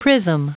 Prononciation du mot prism en anglais (fichier audio)
Prononciation du mot : prism